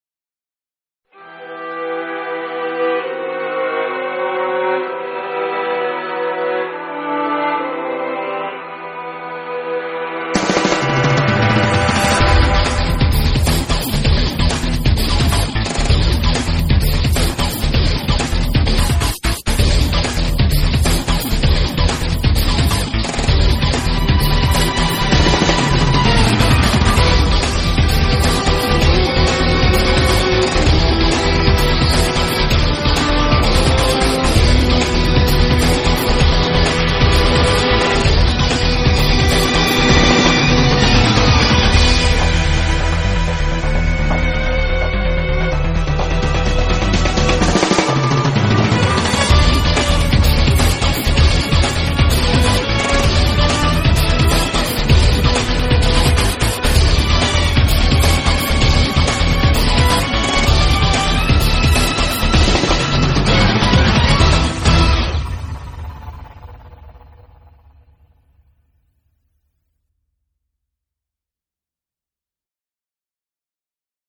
Soundtrack, Electronic Rock